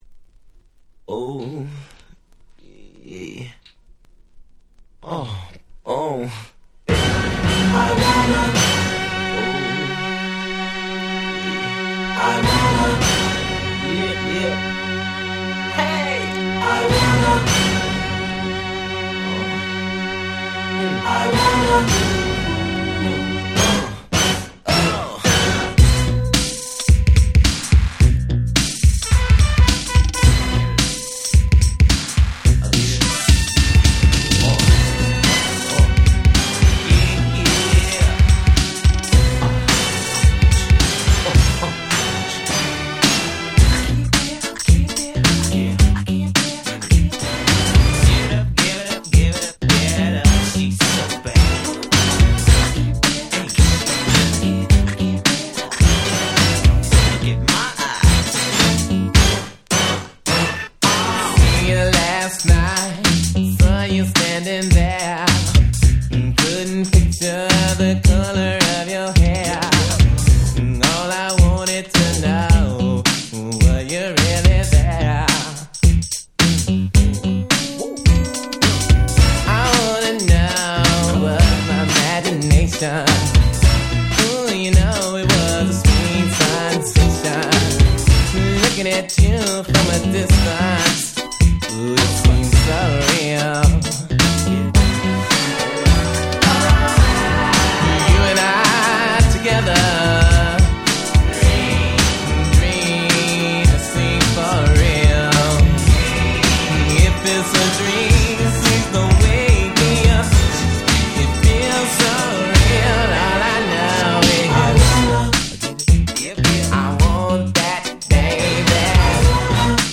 87' Super Hit R&B / New Jack Swing !!
ニュージャックスウィング
ハネ系